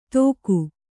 ♪ tōk'u